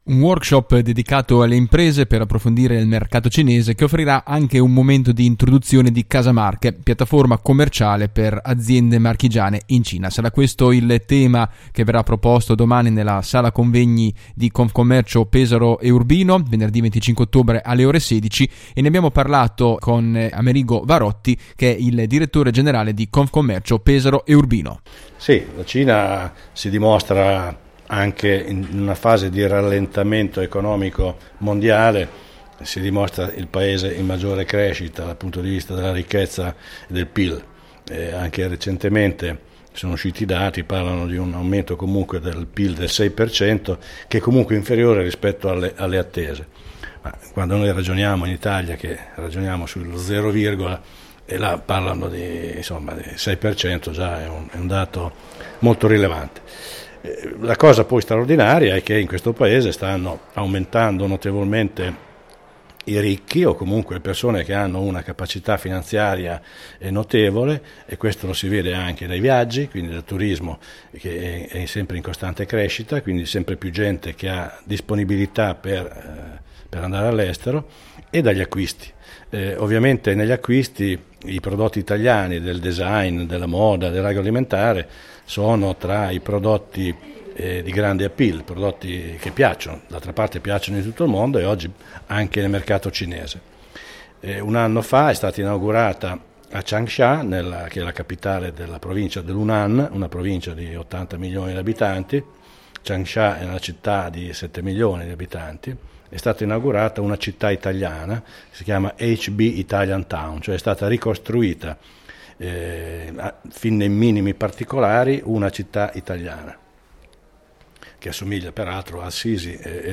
Si terrà domani, Venerdì 25 Ottobre, presso la sala convegni di Confcommercio Pesaro e Urbino, un workshop dedicato alle imprese per approfondire il mercato cinese che offrirà anche un momento di introduzione di “Casa Marche” piattaforma commerciale per le aziende Marchigiane in Cina. La nostra intervista a